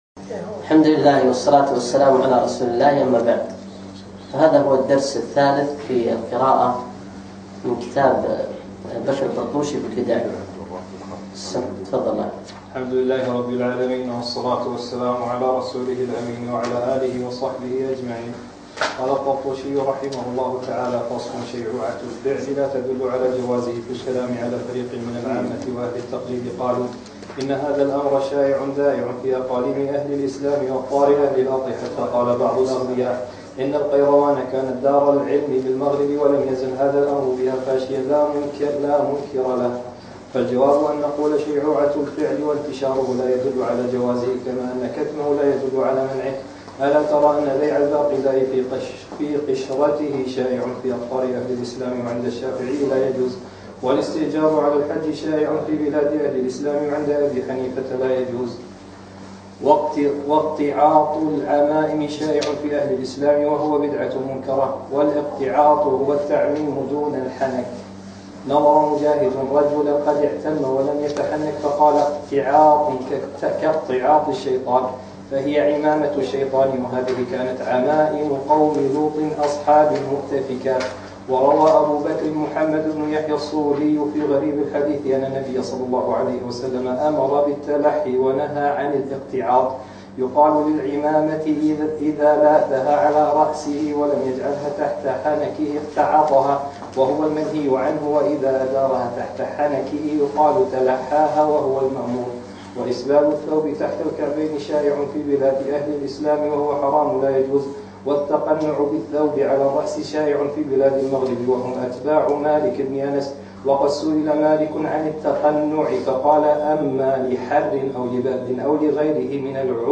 يوم الثلاثاء 10 شوال 1438 الموافق 4 7 2017 في ديوانية مشروع الخالص سعد العبدالله
الدرس الثالث